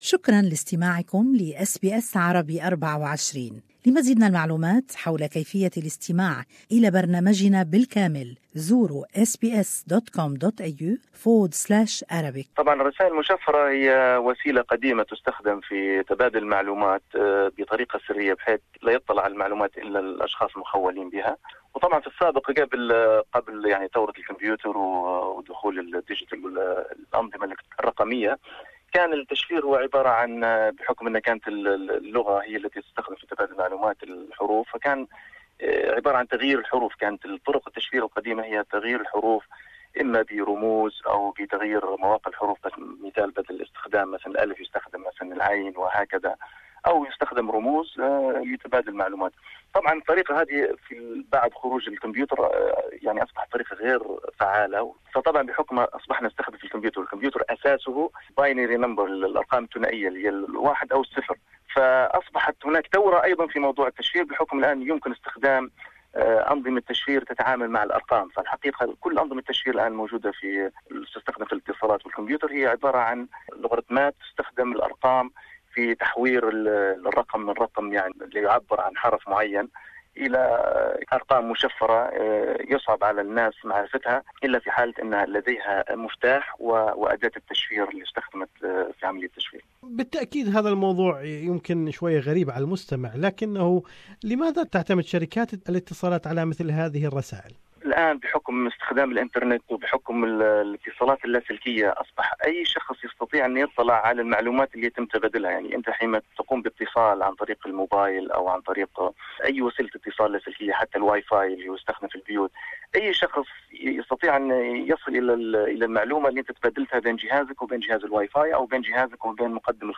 وللتعرف اكثر على اهمية استخدام الشركات لهذا النوع من الرسائل كان لنا هذا اللقاء بخبير التكنلوجيا